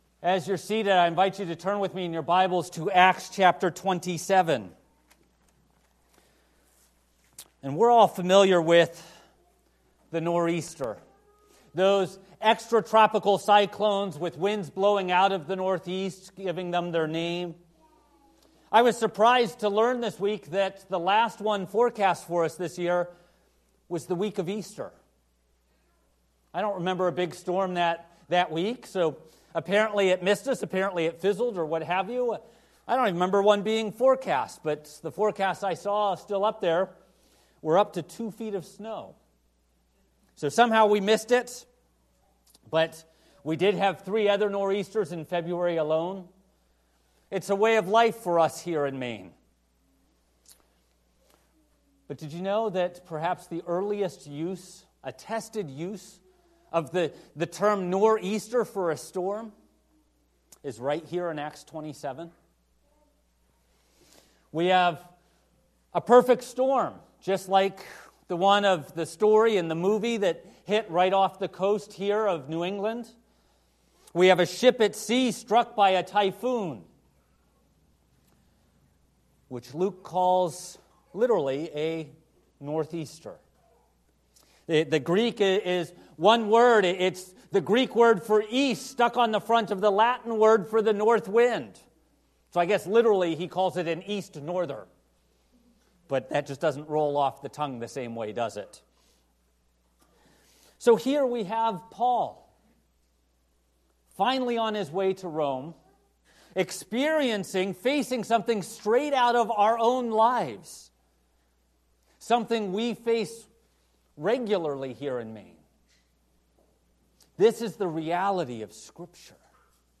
A Presbyterian Church (PCA) serving Lewiston and Auburn in Central Maine